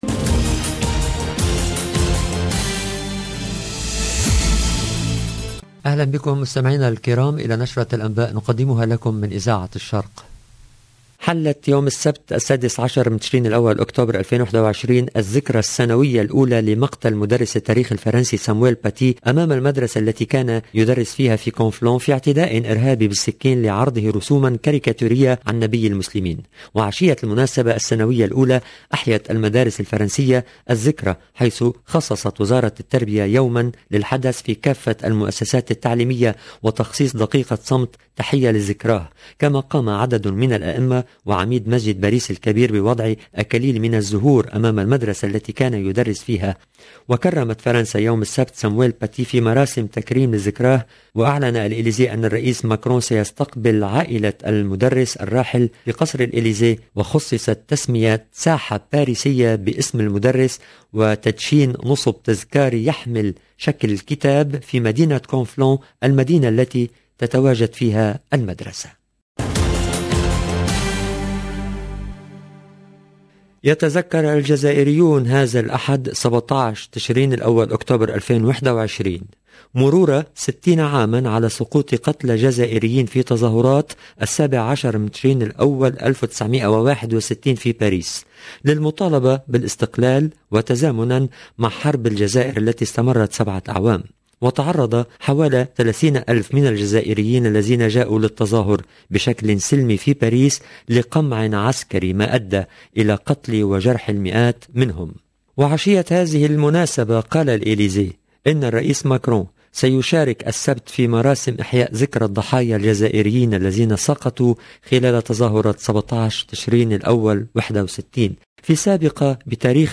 LE JOURNAL DU SOIR EN LANGUE ARABE DU 16/10/2021